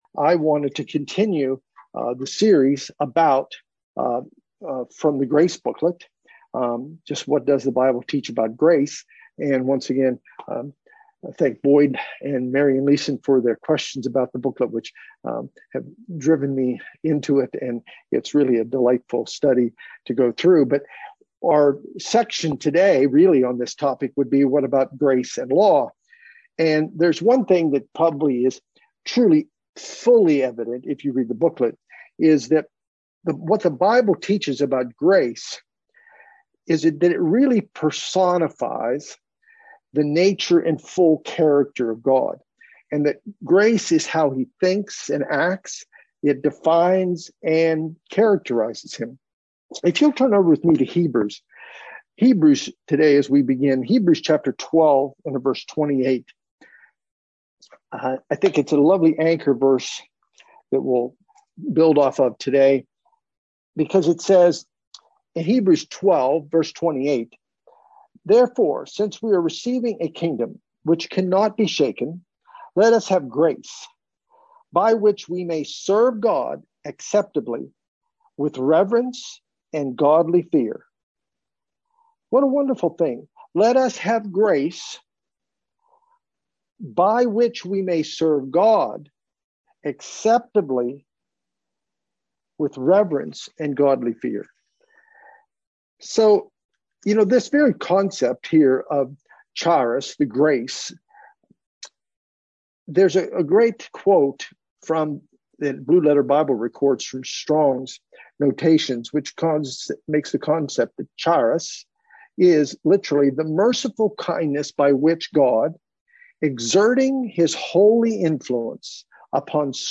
Sermons
Given in Bakersfield, CA Los Angeles, CA